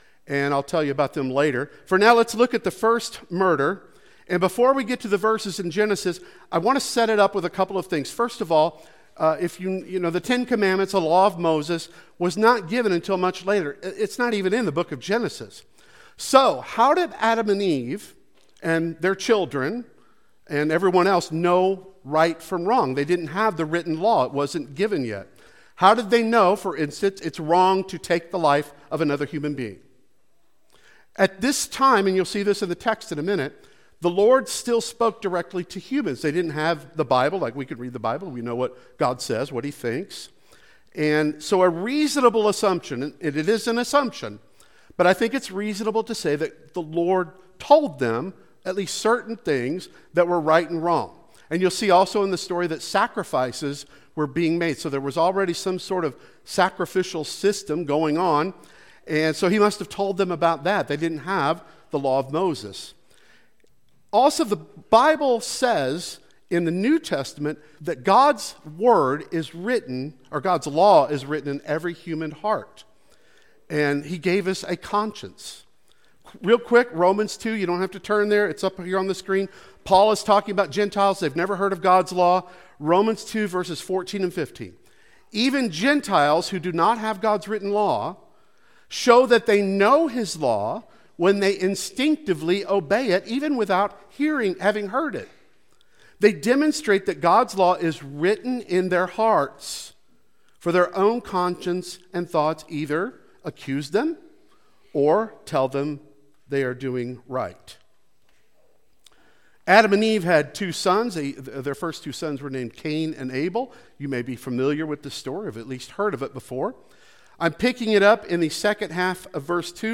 Note: The first few minutes of the sermon were lost due to a technical difficulty.
Beginnings Service Type: Sunday Worship Service Note